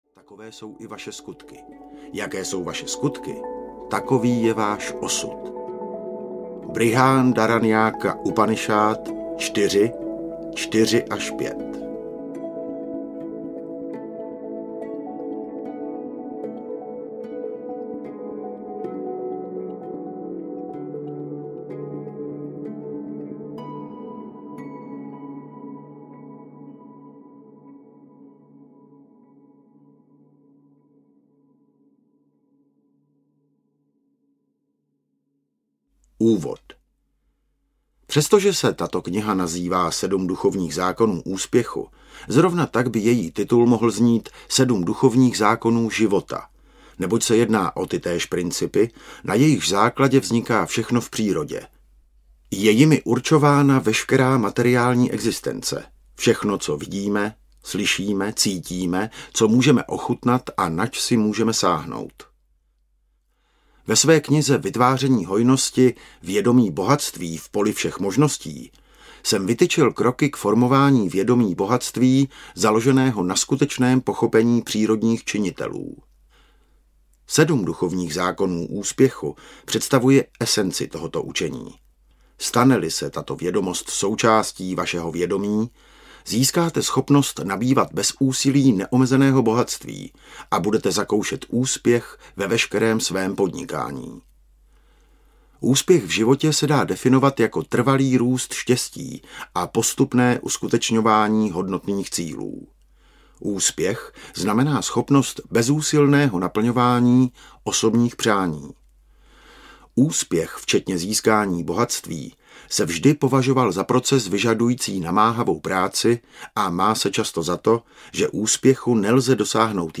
Sedm duchovních zákonů úspěchu audiokniha
Ukázka z knihy